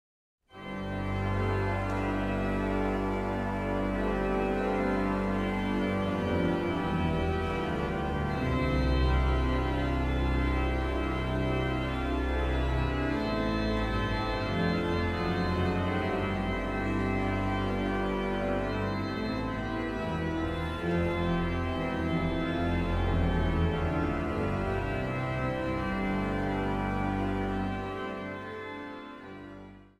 Niet-ritmisch gezongen Psalmen met tegenstem
orgel
Zang | Gemengd koor